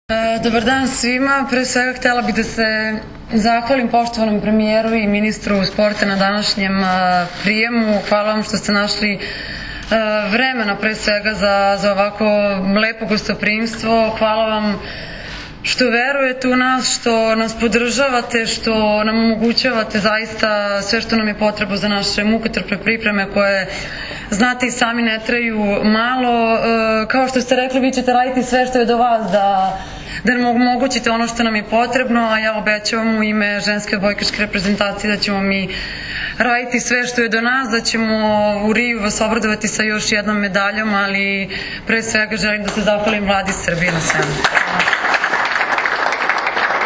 Predsednik Vlade Srbije priredio prijem za bronzane odbojkašice
IZJAVA MAJE OGNJENOVIĆ